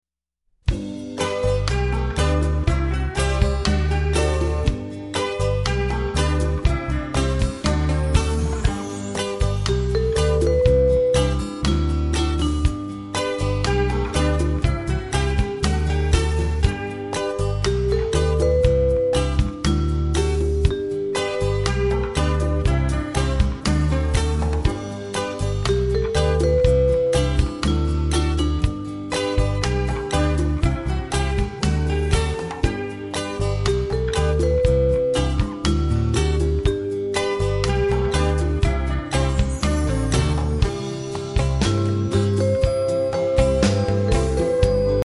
Listen to a sample of the instrumental.